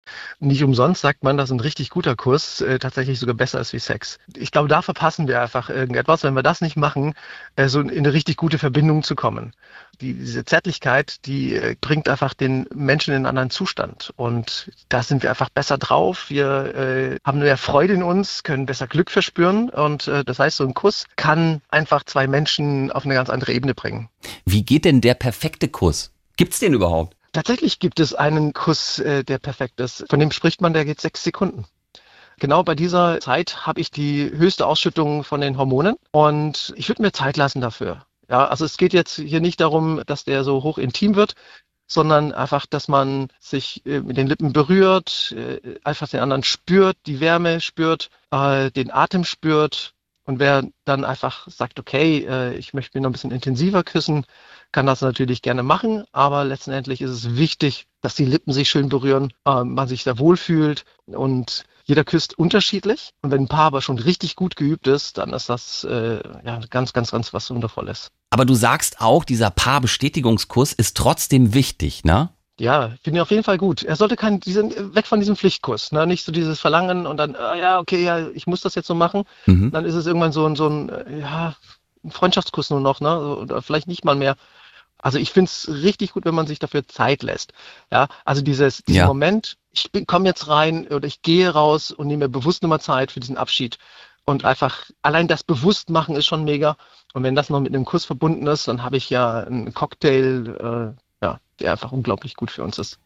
SWR3 Interview